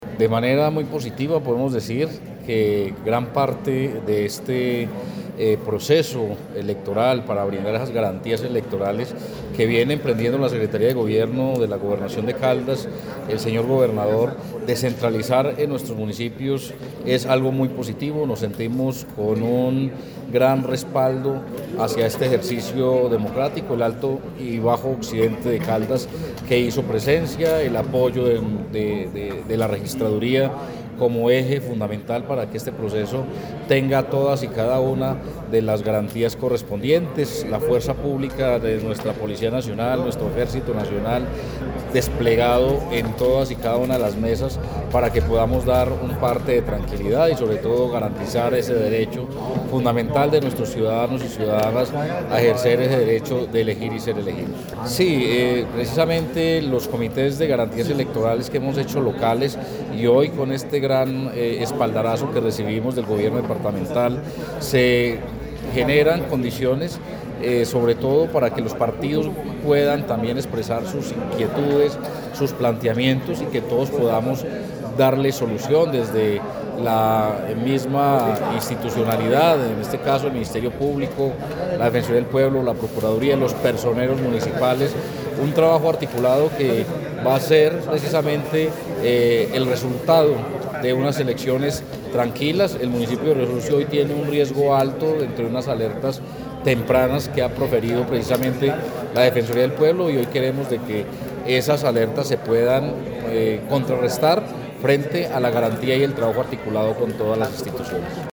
Abel David Jaramillo Largo, alcalde de Riosucio.